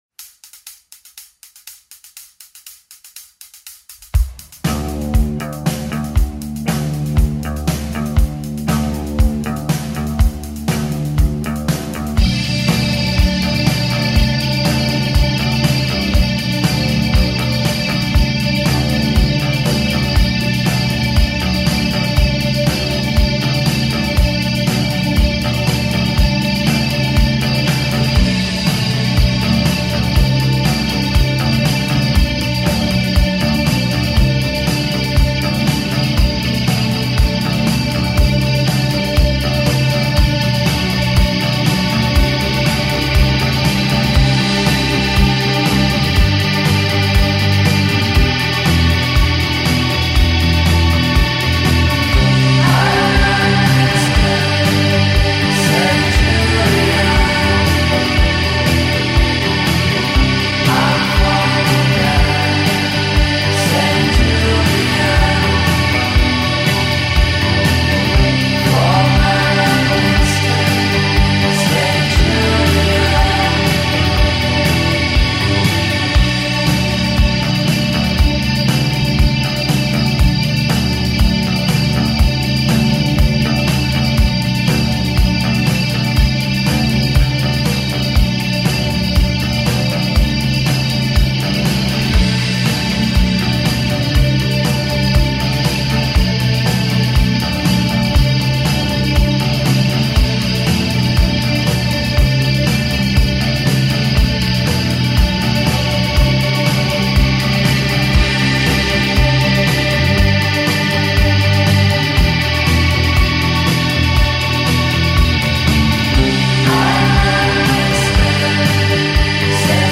euphoric dream pop
Recorded in Melbourne